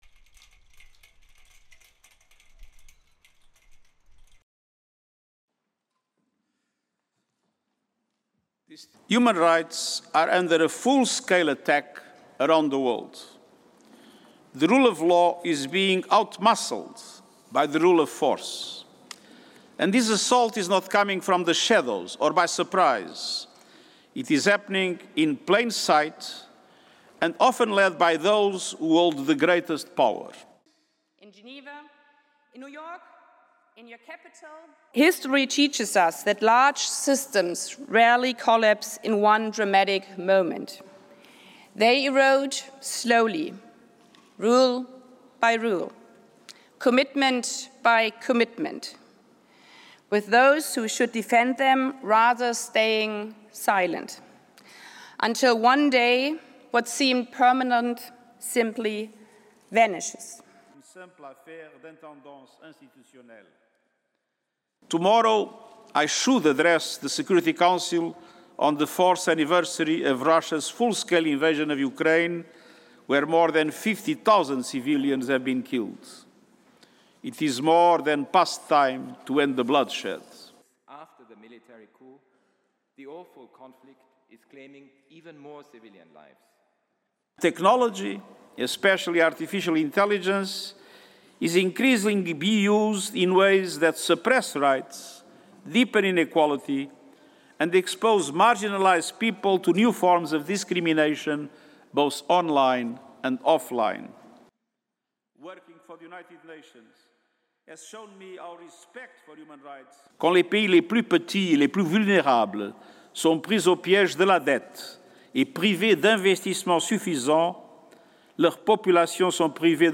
DATELINE: 23 FEBRUARY 2025 GENEVA, SWITZERLAND
• António Guterres, UN Secretary-General
• Annalena Baerbock, President of the UN General Assembly
15. Wide, Mr. Guterres finishing his address at lectern, Human Rights Council officials applaud.